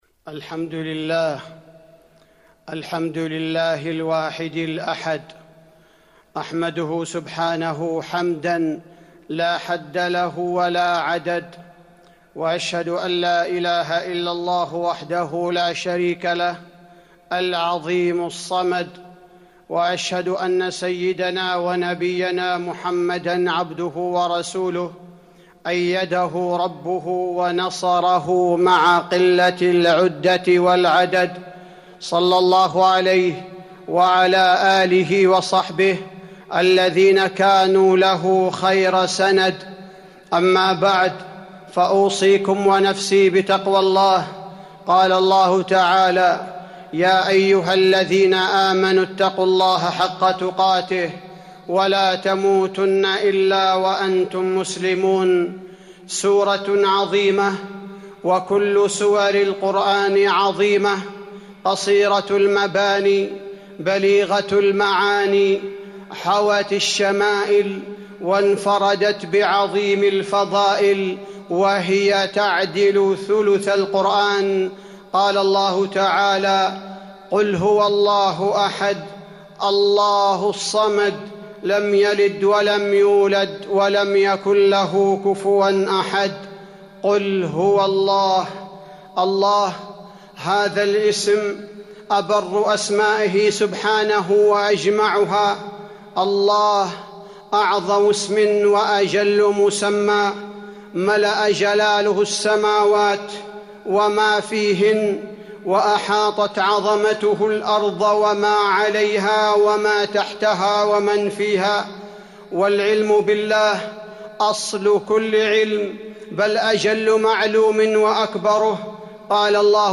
تاريخ النشر ٩ محرم ١٤٤٢ هـ المكان: المسجد النبوي الشيخ: فضيلة الشيخ عبدالباري الثبيتي فضيلة الشيخ عبدالباري الثبيتي من هدايات سورة الإخلاص